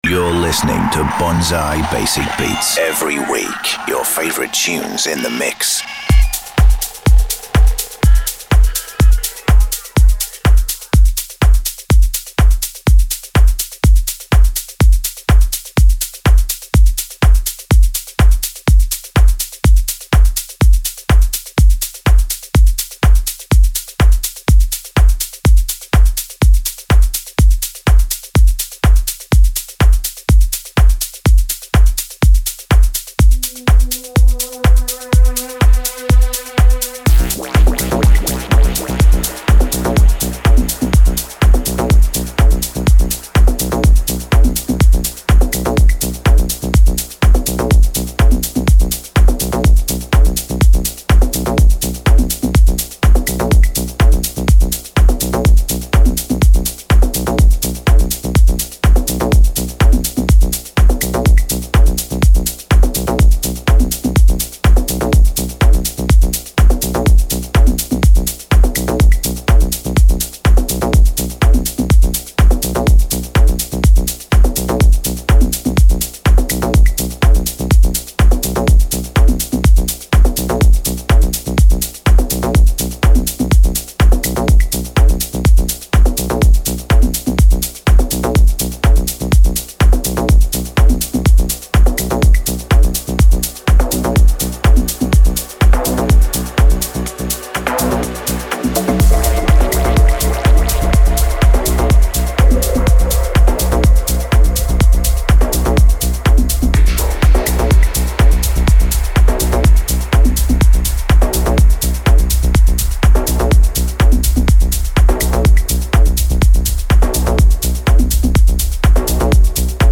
finely tuned mix featuring tracks and remixes